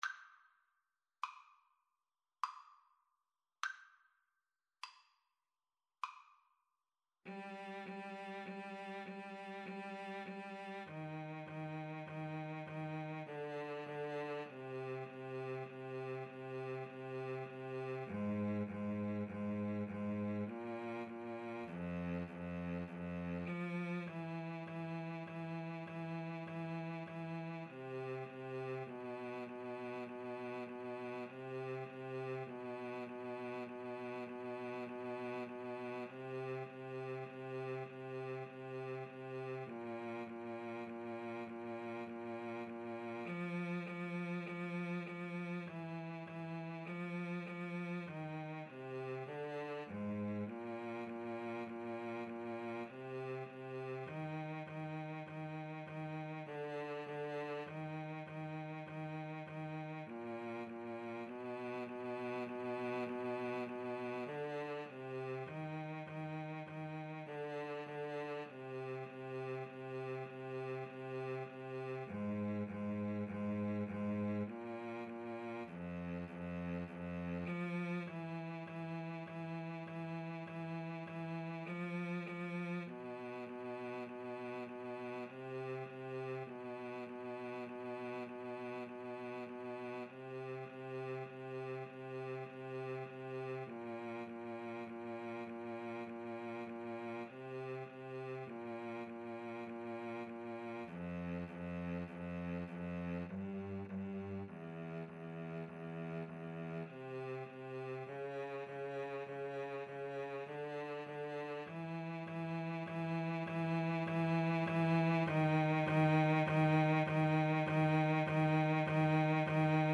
Play (or use space bar on your keyboard) Pause Music Playalong - Player 1 Accompaniment reset tempo print settings full screen
Eb major (Sounding Pitch) (View more Eb major Music for Cello Duet )
Andantino = 50 (View more music marked Andantino)
Classical (View more Classical Cello Duet Music)